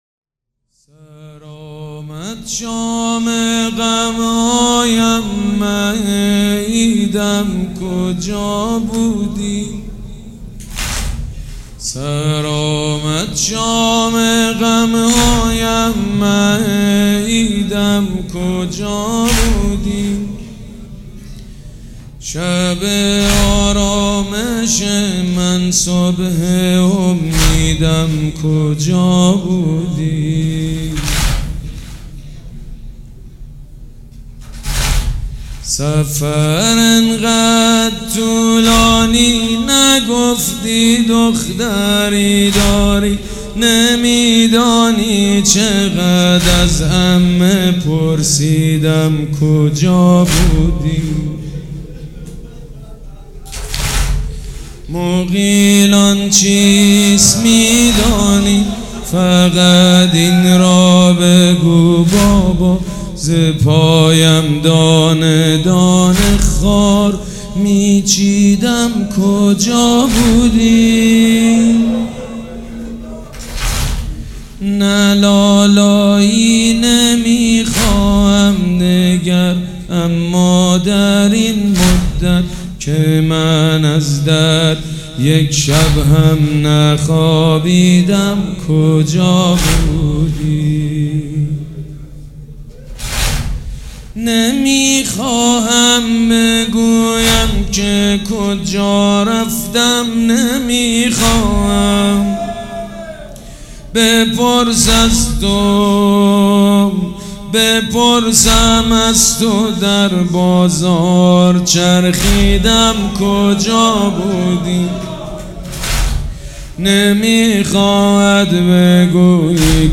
حاج سید مجید بنی فاطمه
مراسم عزاداری شب سوم